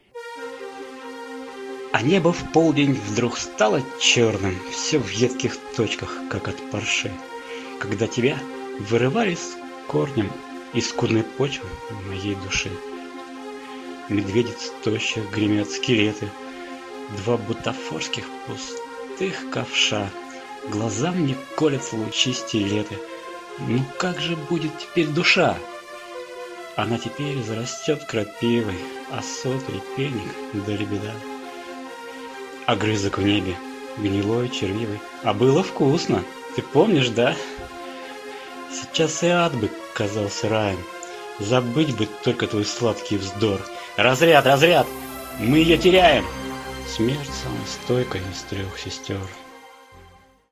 Прослушать в авторском исполнении: